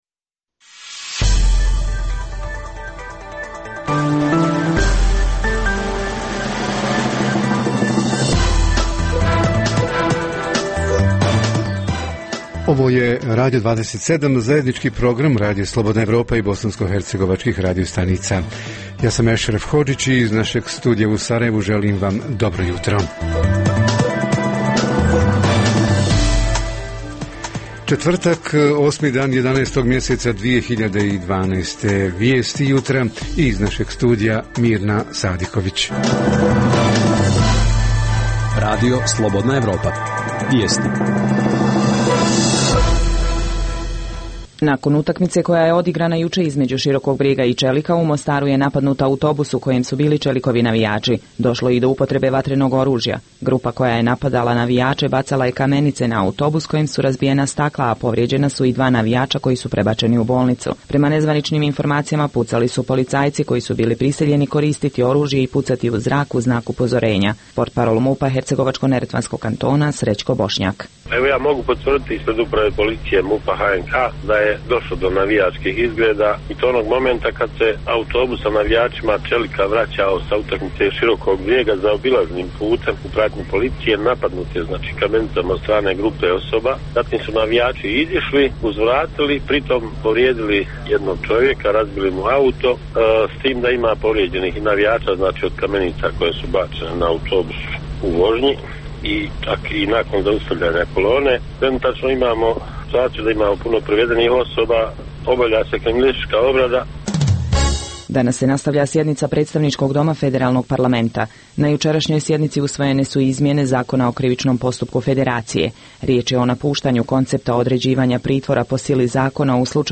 Susreti uživo, sa Bijeljinom